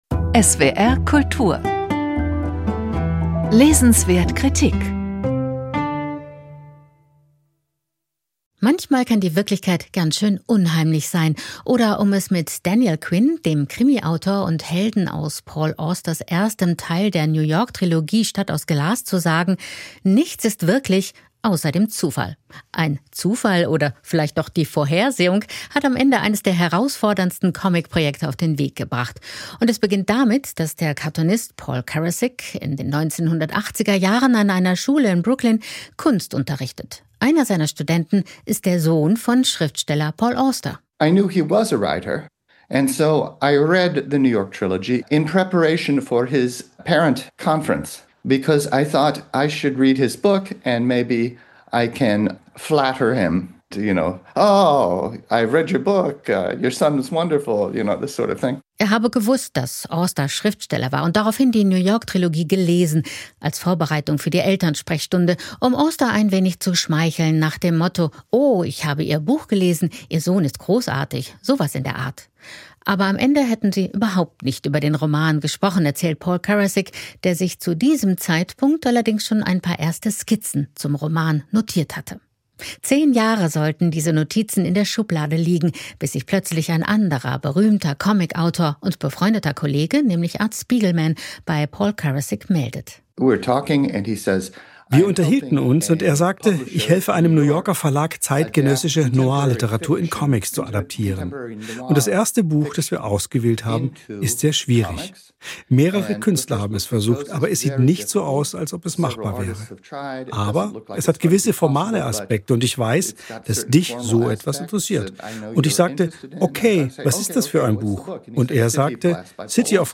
Rezension von